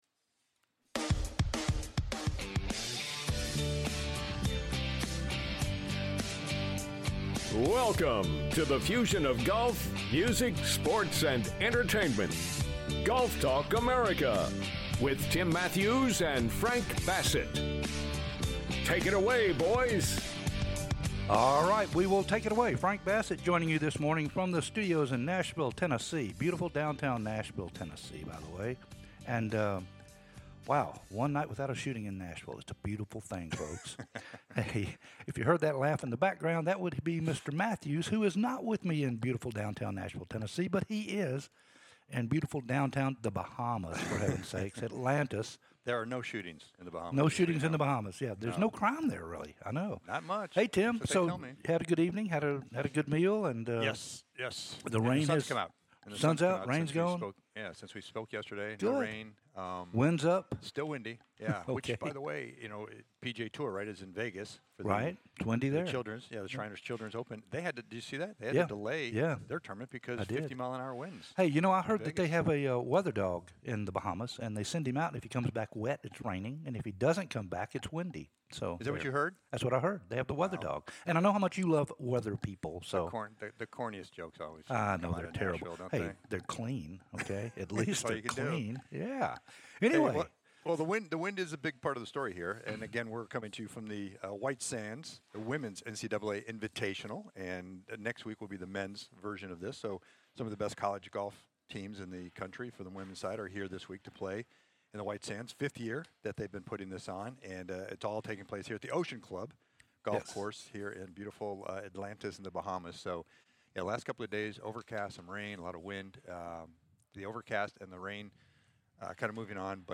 "LIVE" AT THE OCEAN COURSE IN THE BAHAMAS. THE NCAA WHITE SANDS INVITATIONAL